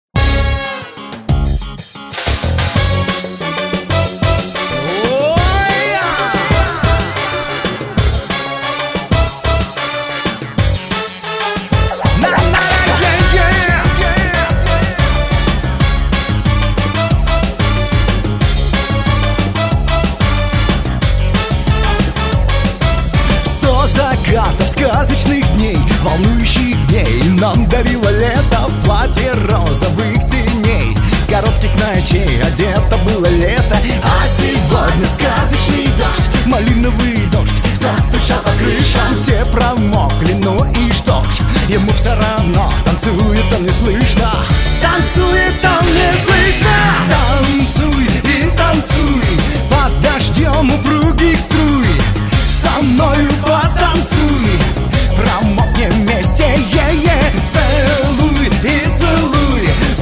Pop
Этакая добротная советская эстрада времен Раймонда Паулса.